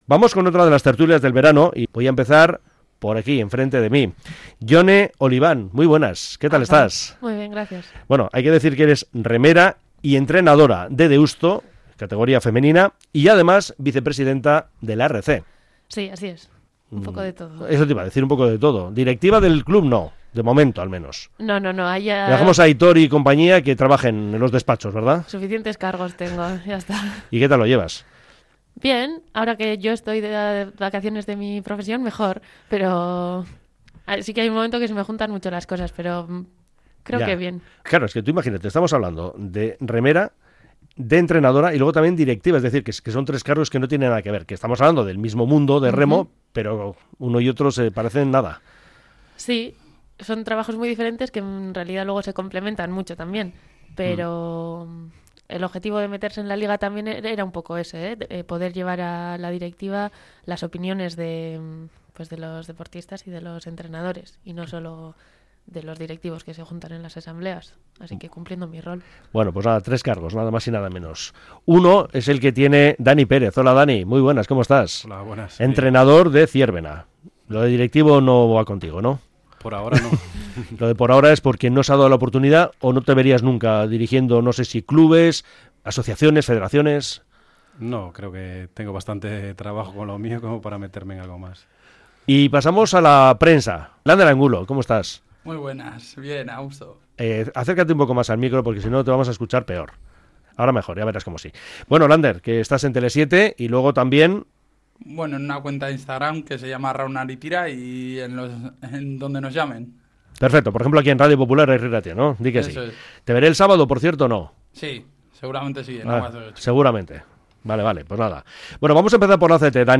TERTULIA-REMO.mp3